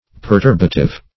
Meaning of perturbative. perturbative synonyms, pronunciation, spelling and more from Free Dictionary.
Search Result for " perturbative" : The Collaborative International Dictionary of English v.0.48: Perturbative \Per"tur*ba*tive\, a. Tending to cause perturbation; disturbing.